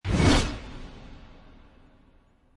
dramatic-camera-zoom-6393.mp3